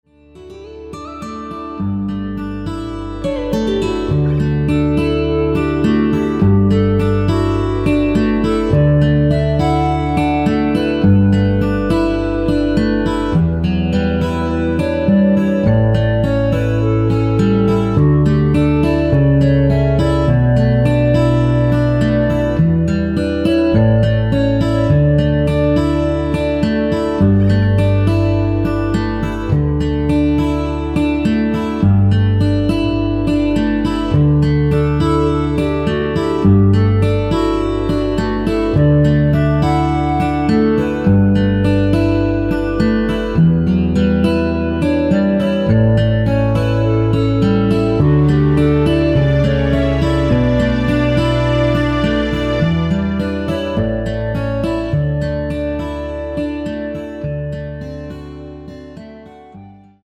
1절 편곡 멜로디 포함된 MR 입니다.
D
앞부분30초, 뒷부분30초씩 편집해서 올려 드리고 있습니다.
중간에 음이 끈어지고 다시 나오는 이유는